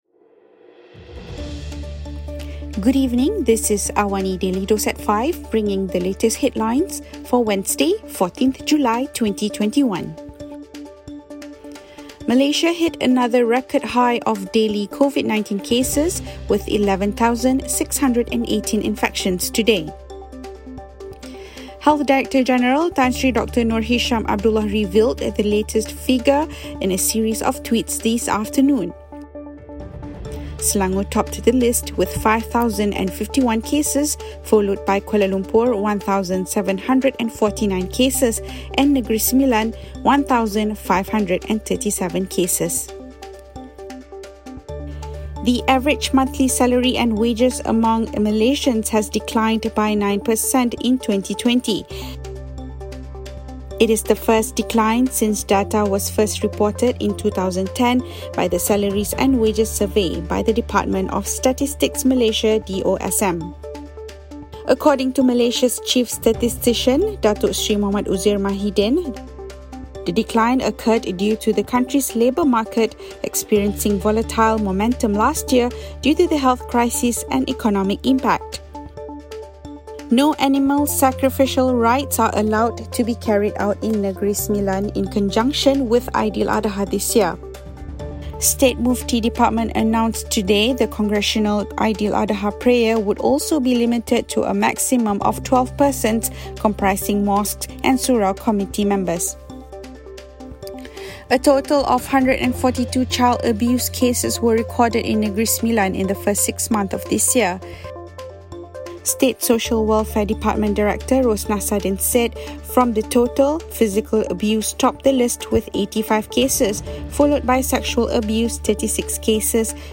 Also, Switzerland's Roger Federer became the latest big name in tennis to withdraw from the Tokyo Olympics due to a knee injury during the grasscourt season. Listen to the top stories of the day, reporting from Astro AWANI newsroom — all in 3 minutes.